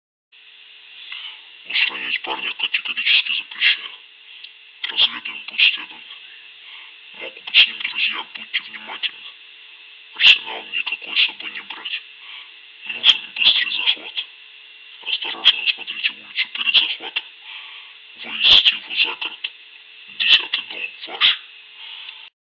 - Джони, спасибо что приехал. Смотри... по моим каналам удалось перехватить и расшифровать звонок,